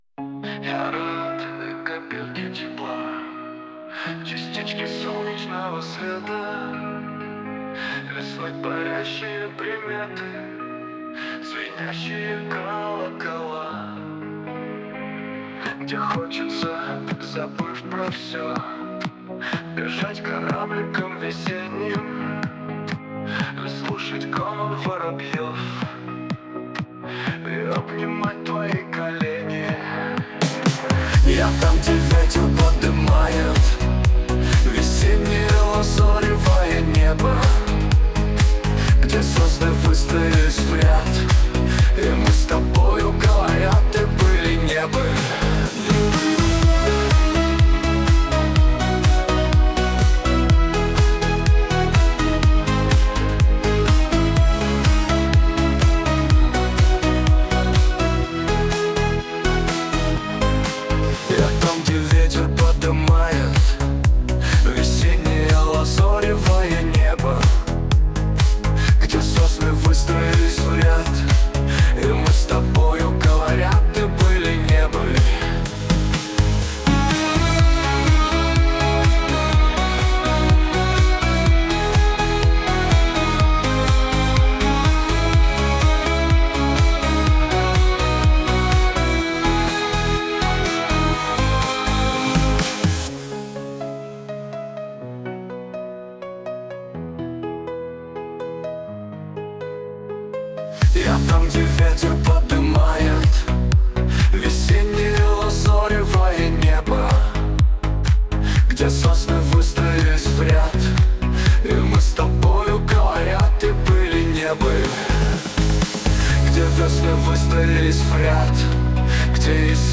Песня о наступлении Весны в танцевальных ритмах
Дискотека